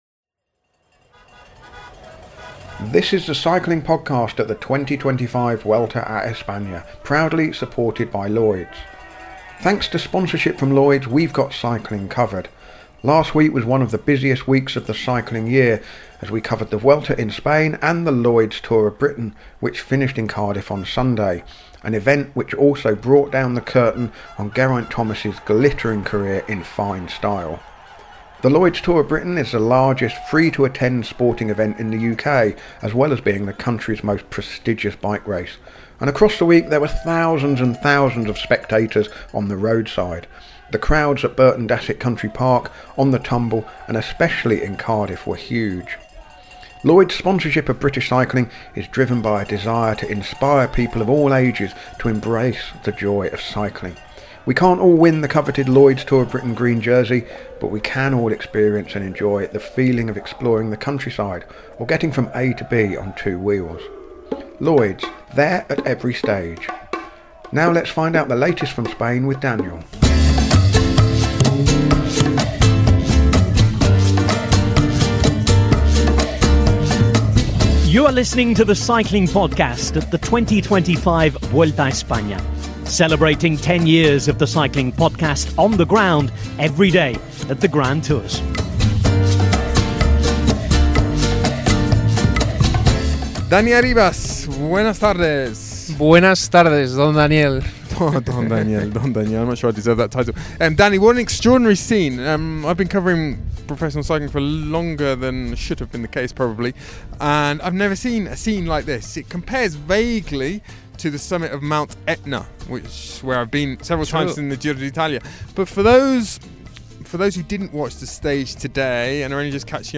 Join us for daily coverage of the Vuelta a España recorded on the road as the race makes its way from Turin to Madrid. Our daily coverage features race analysis, interviews and daily postcards from Spain.